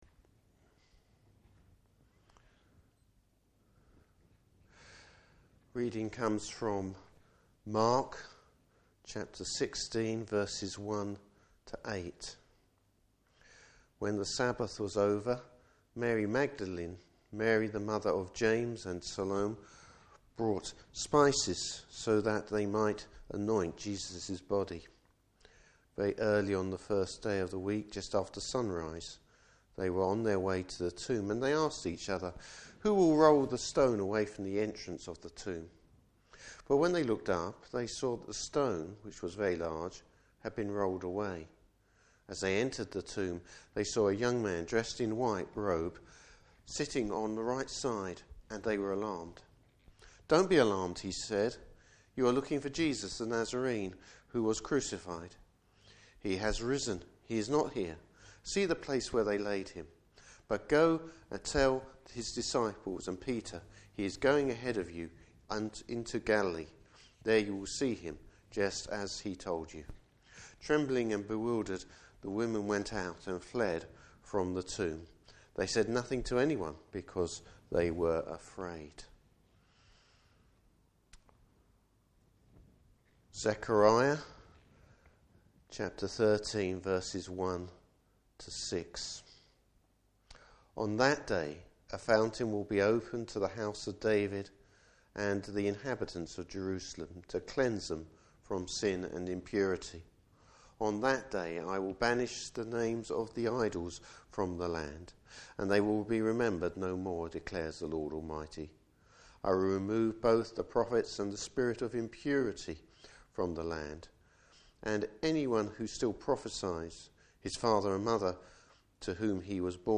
Service Type: Easter Day Morning Service.